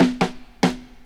Old School Fill.wav